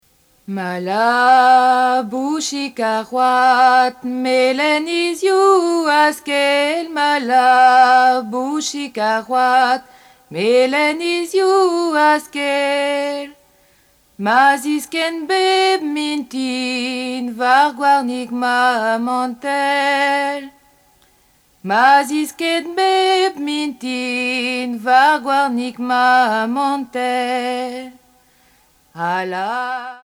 Origine : Bretagne (pays Bigouden)
chant Bigouden